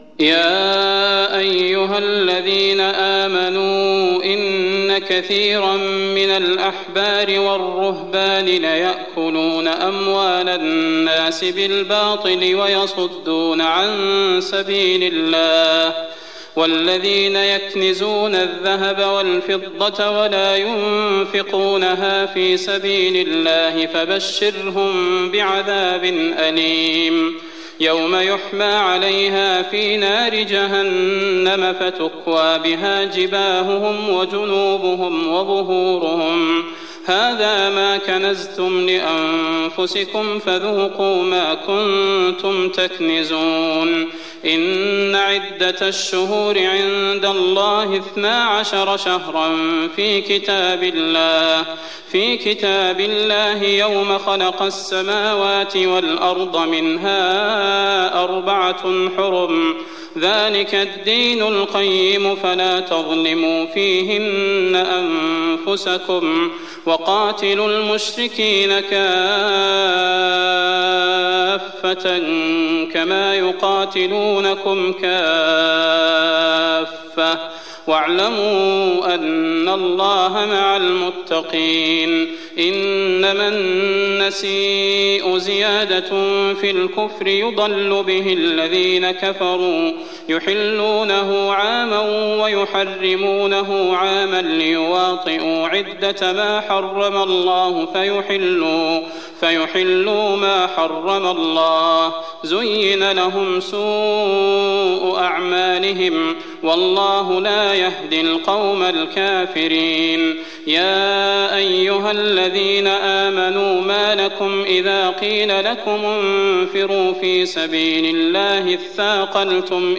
تراويح الليلة العاشرة رمضان 1419هـ من سورة التوبة (34-96) Taraweeh 10th night Ramadan 1419H from Surah At-Tawba > تراويح الحرم النبوي عام 1419 🕌 > التراويح - تلاوات الحرمين